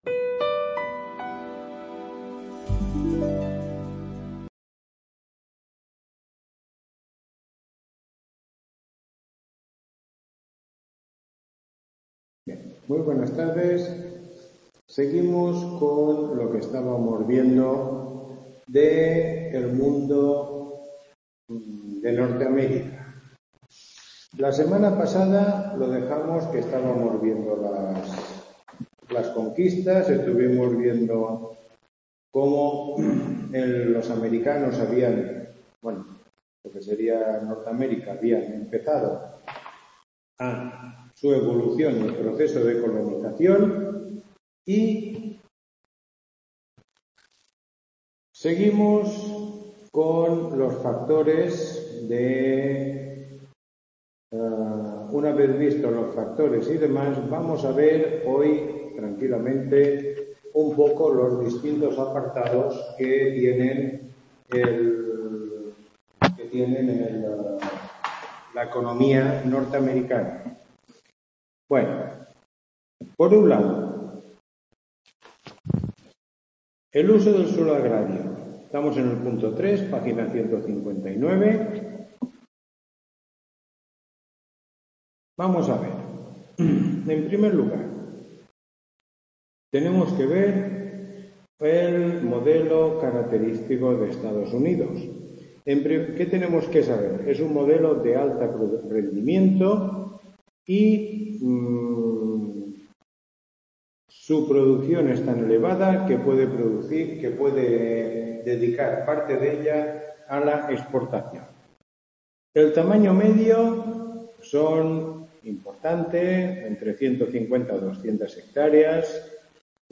Tutoría 06